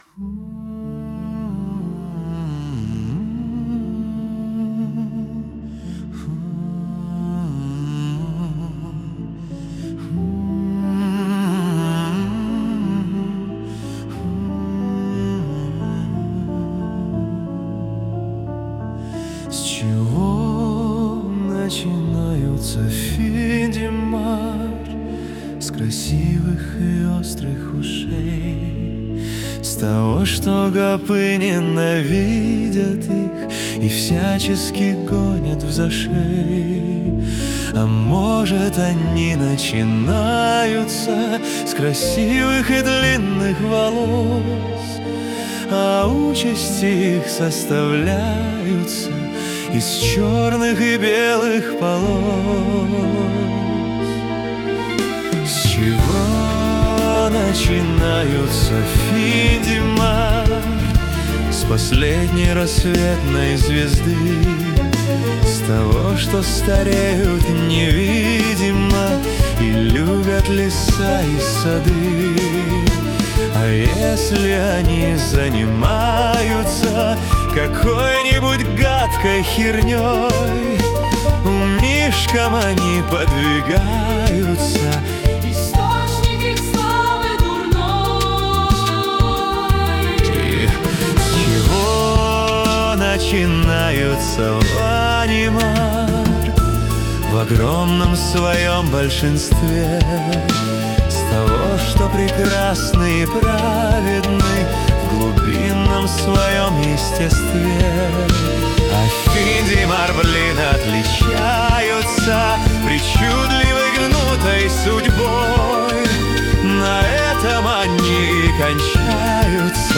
О разнице между ванимар и финдимар (пока что без подробностей, просто песня):